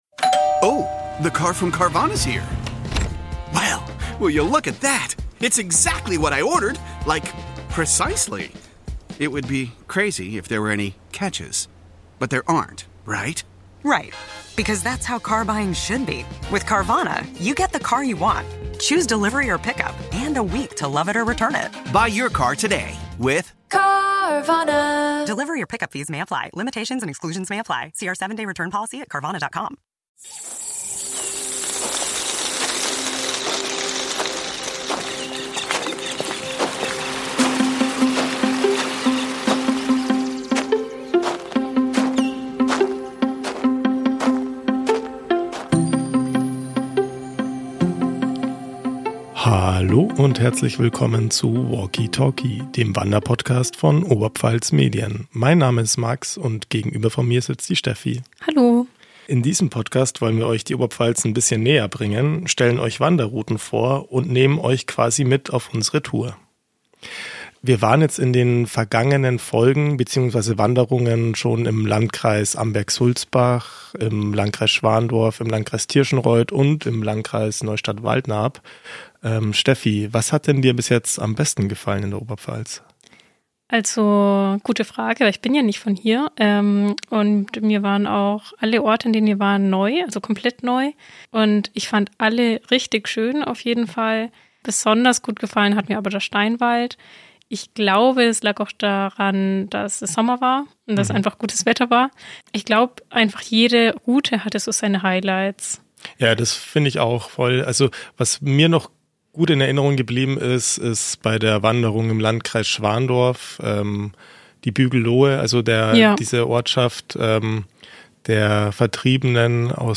Vom Tal in Högen führt der Weg bei Frost bergauf zur Burgruine Lichtenegg.